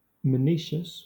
Ääntäminen
Southern England
IPA : /məˈniː.ʃəs/